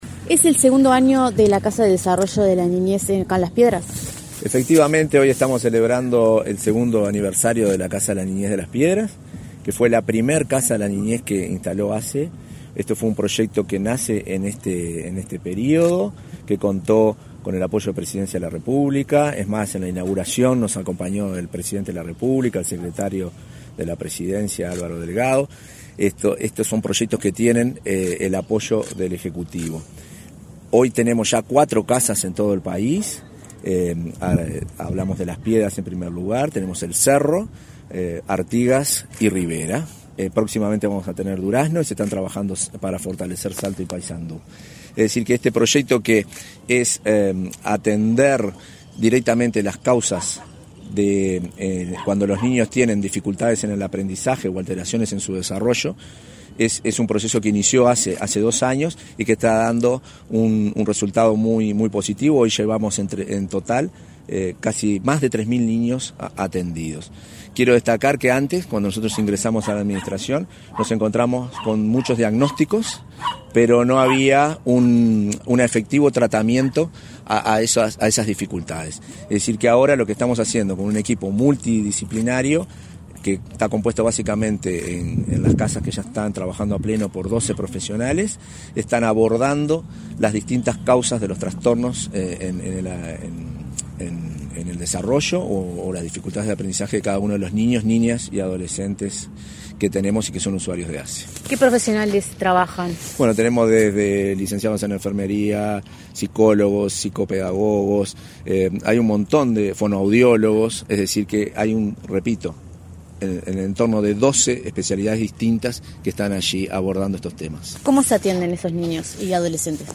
Entrevista al vicepresidente de ASSE, Marcelo Sosa | Presidencia Uruguay
Tras la actividad, el vicepresidente de ASSE, Marcelo Sosa, realizó declaraciones a Comunicación Presidencial.